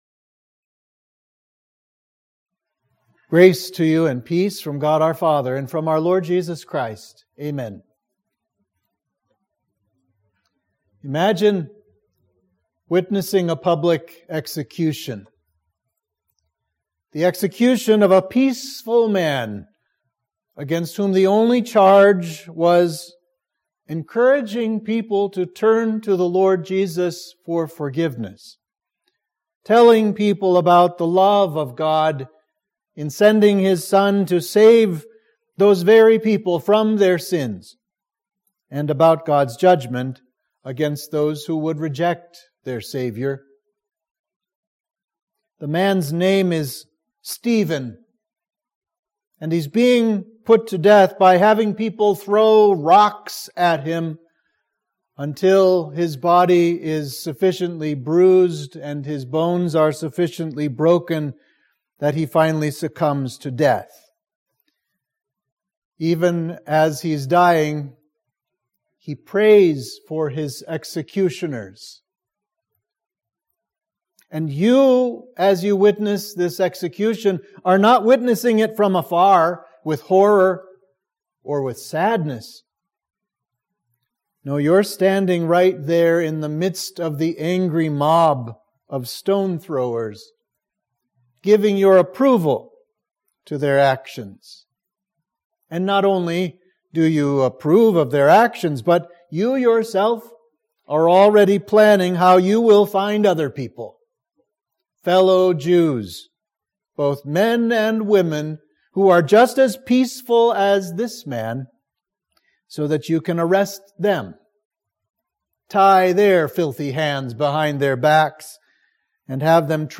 Sermon for the Conversion of Paul (observed)